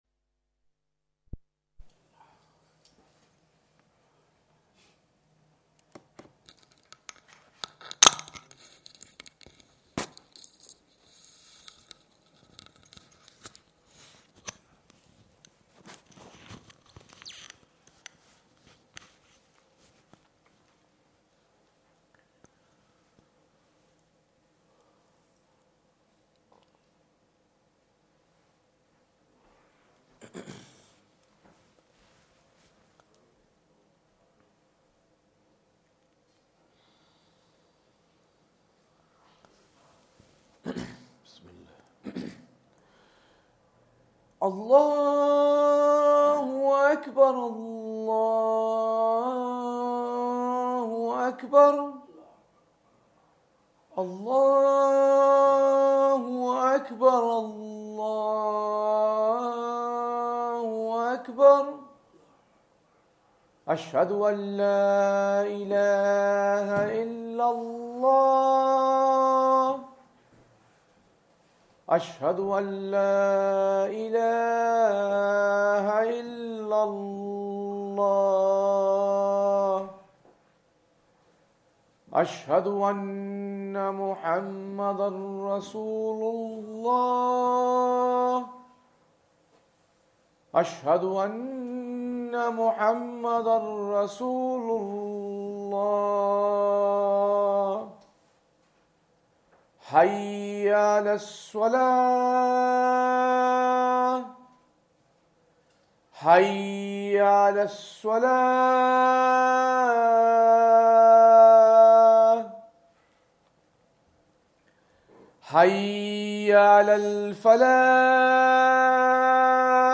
Azan
Madni Masjid, Langside Road, Glasgow